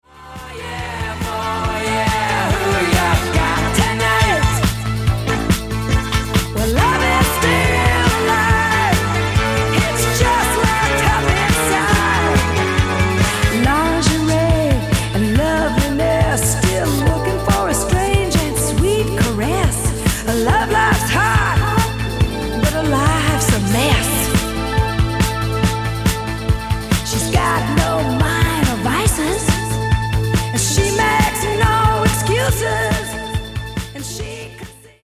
Genere:   Disco Elettronica